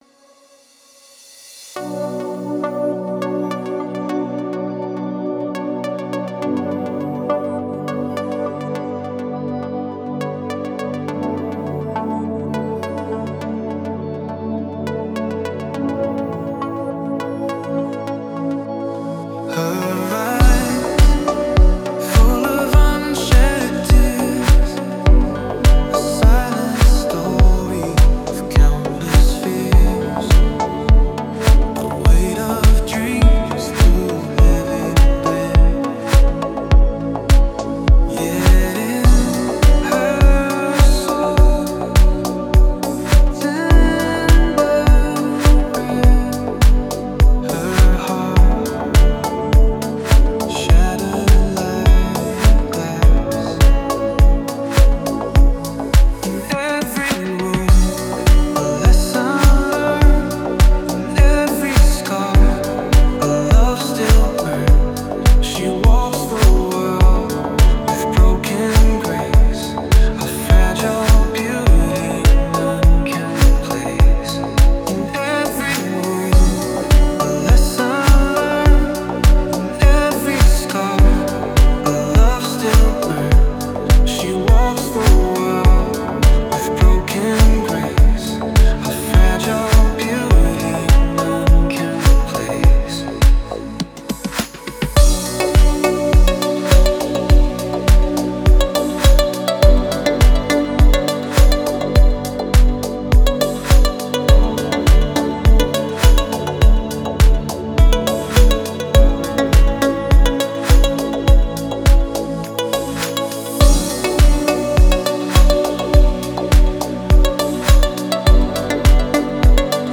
Спокойная музыка
спокойные песни